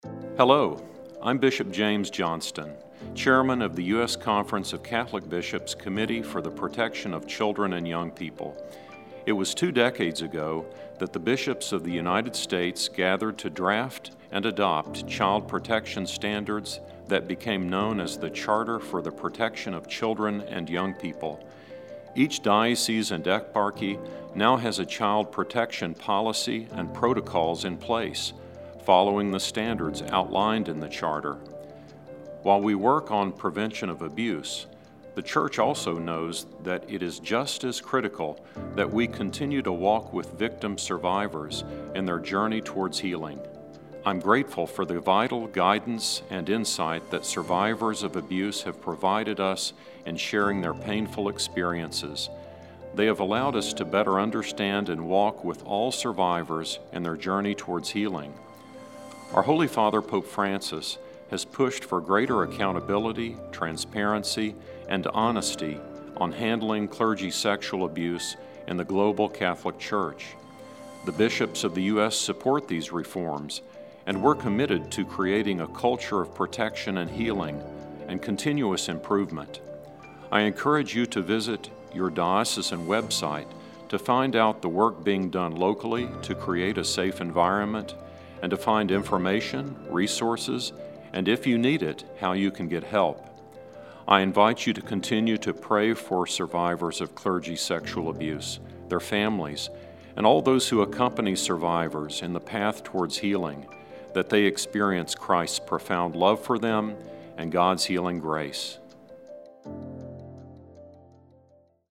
Bp. Johnston Chairman, Committee on Child and Youth Protection Radio Spot